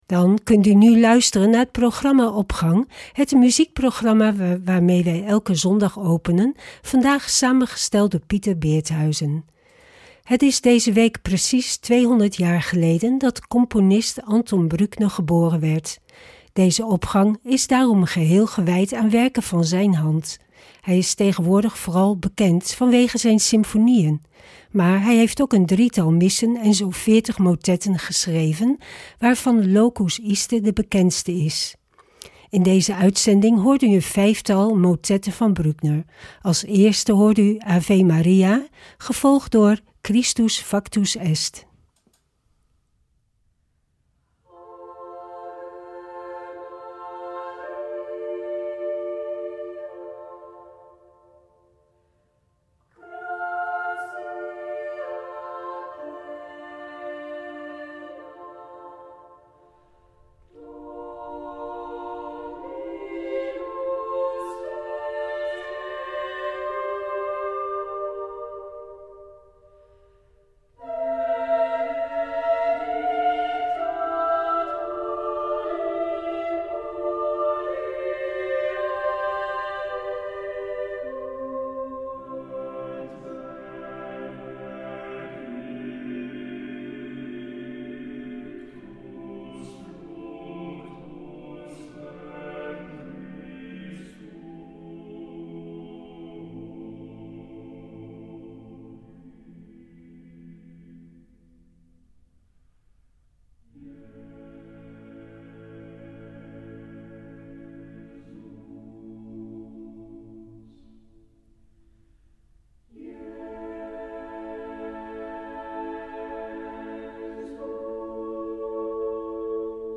Opening van deze zondag met muziek, rechtstreeks vanuit onze studio.
motetten
In deze uitzending van Opgang hoort u een vijftal motetten van Bruckner voor koor a capella en met begeleiding.